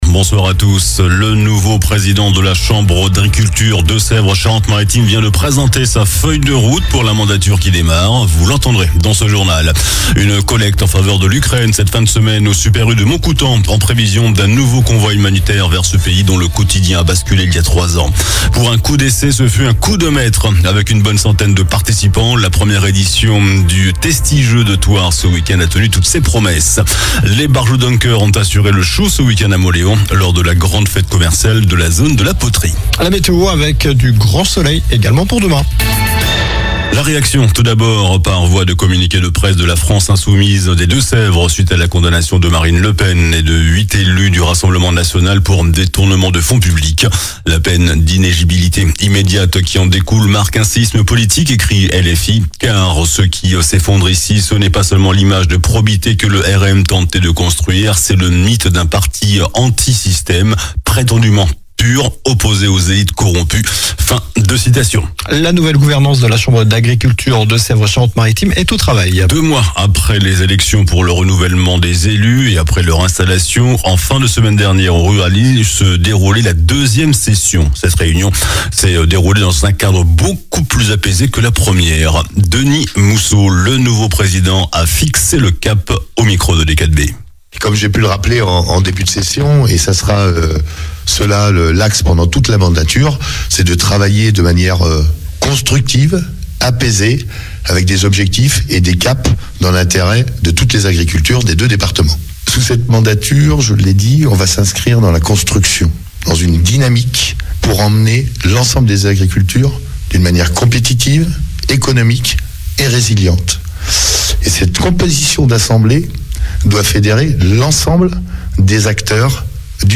JOURNAL DU LUNDI 31 MARS ( SOIR )